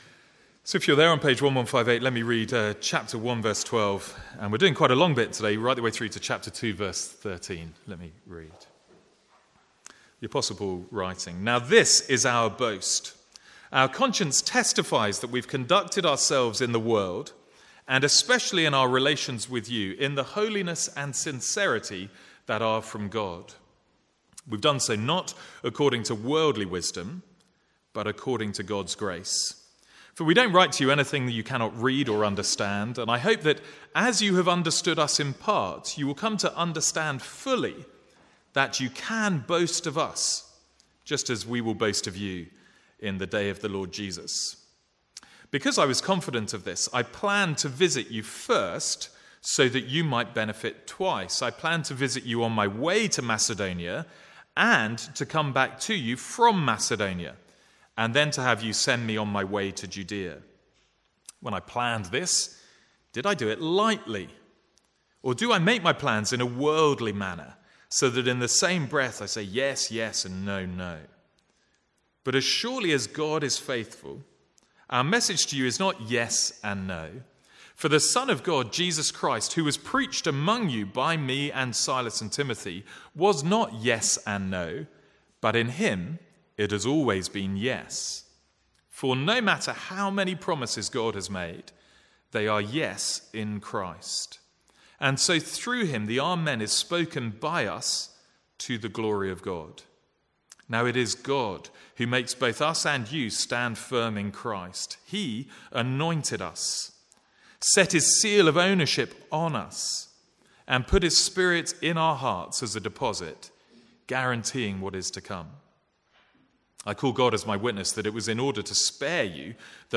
From the Sunday morning series in 2 Corinthians.